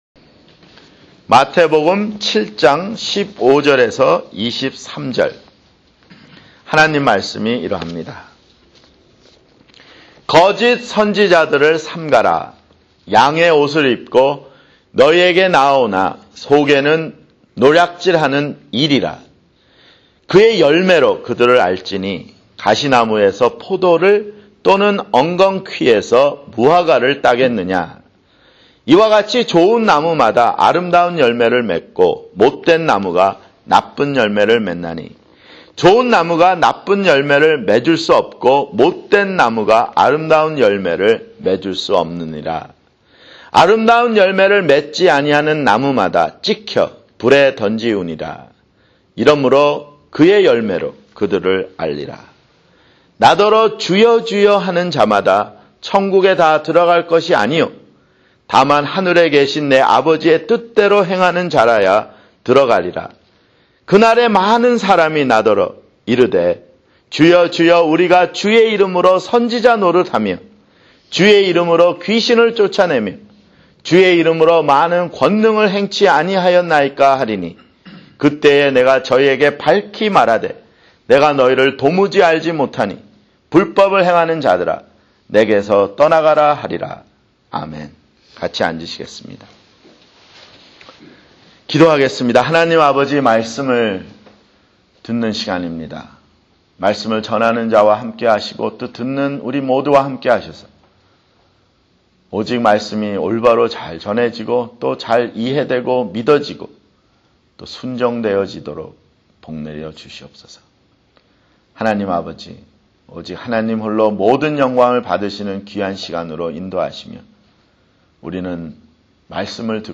[주일설교] 마태복음 (46)